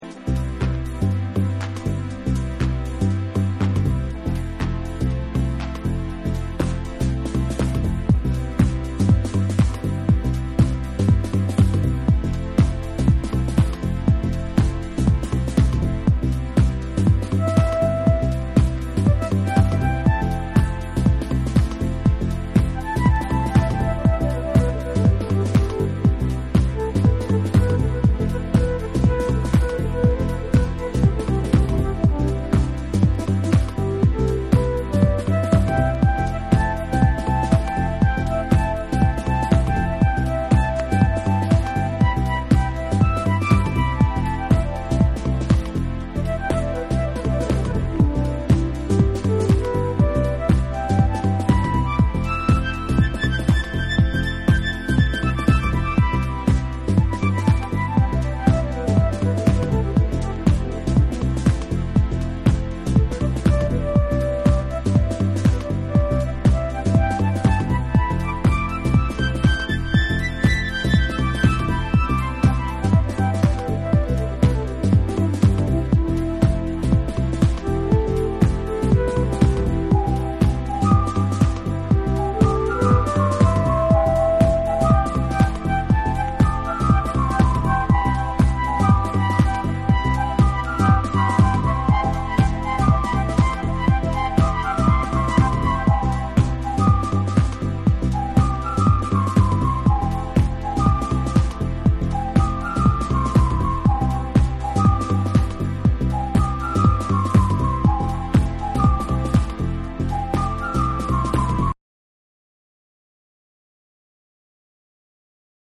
スイングしまくる4/4トラックとベースライン
TECHNO & HOUSE